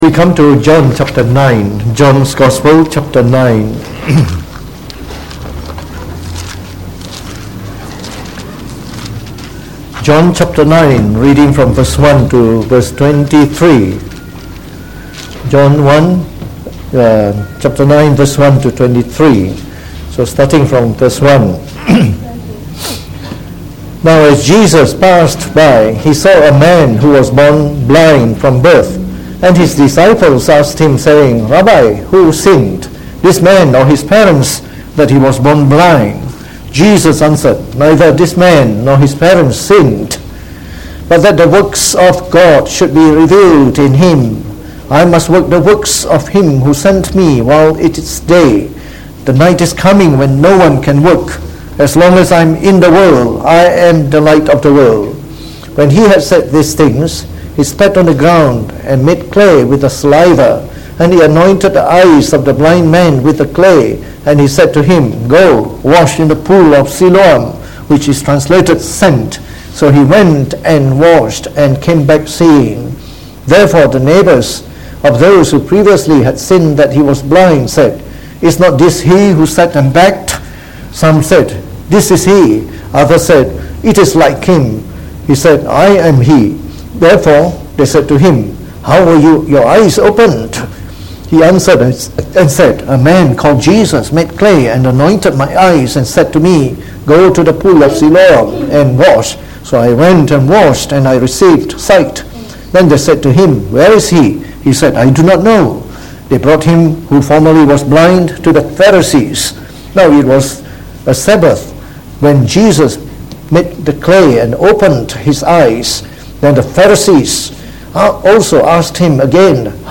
Preached on the 6th January 2019.